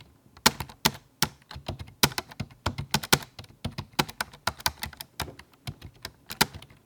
keyboard gaming
click computer computer-keyboard fast gaming key keyboard letters sound effect free sound royalty free Sound Effects